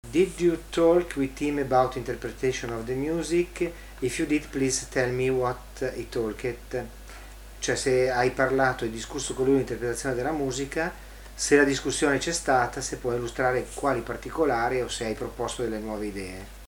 Una intervista